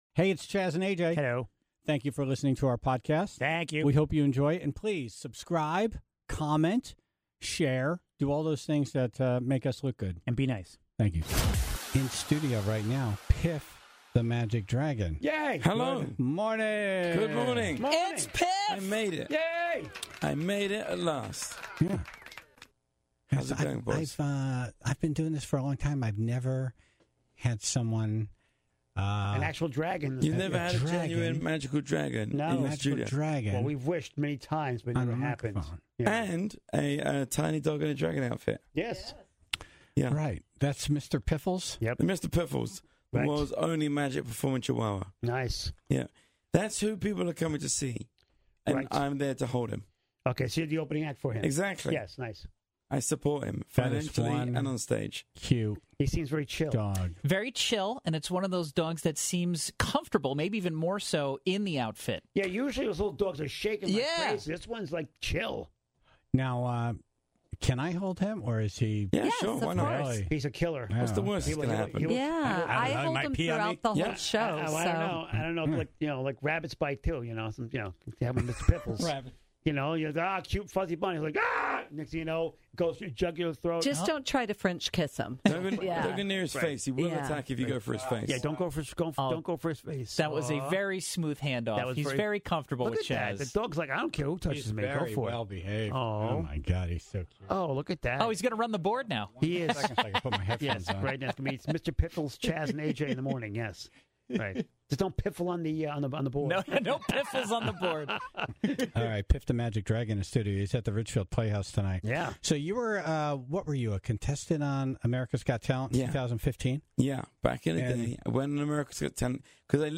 Wednesday, May 17: Piff The Magic Dragon In Studio; Dumb Ass News; Stump The Chumps - Foo Fighters Trivia